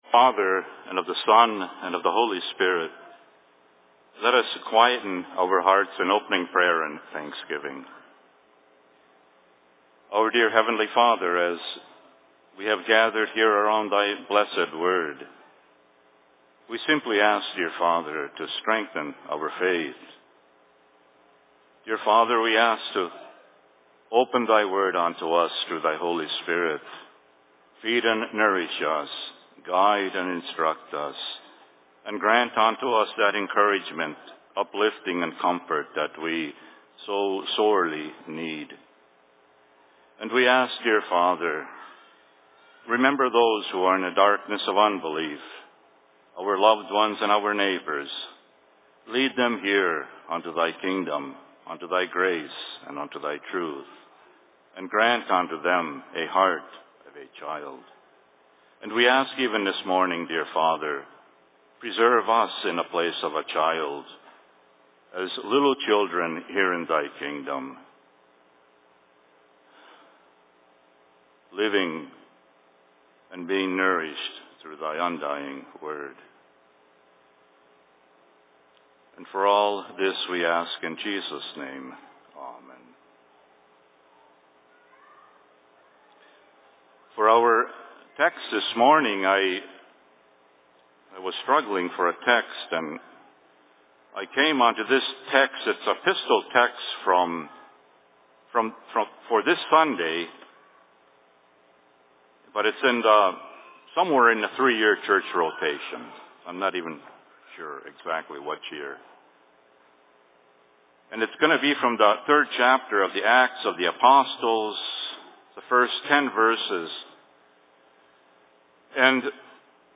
Services/Sermon in Rockford 19.08.2018
Location: LLC Rockford